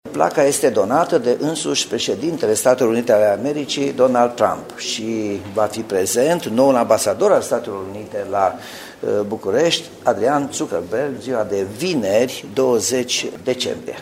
Placheta de bronz va fi amplasată pe fațada Garnizoanei, unde va fi viitorul Muzeu Național al Revoluției, spune primarul Nicolae Robu.